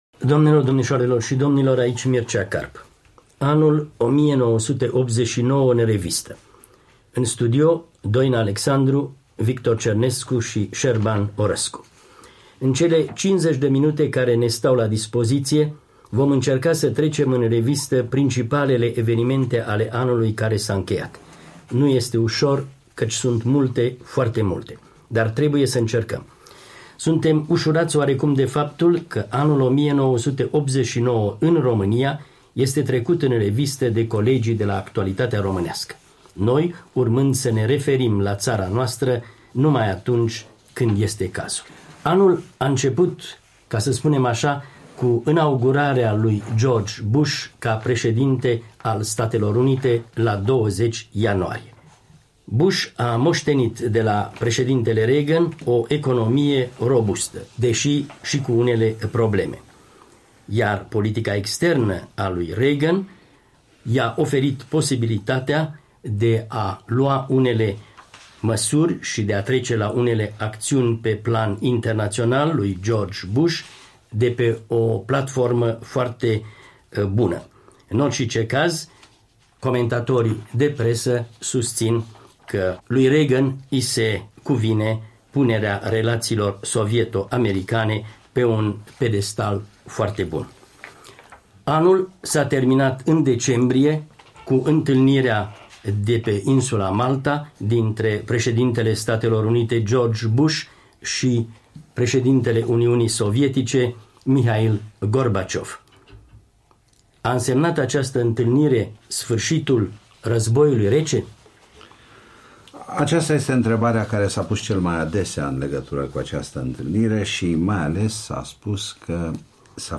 Anul internațional 1989, o emisiune de bilanț